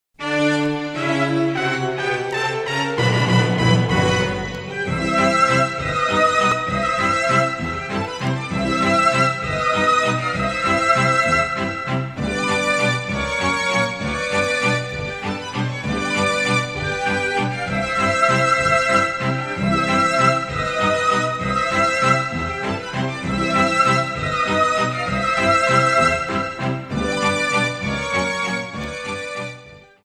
Cut off and fade-out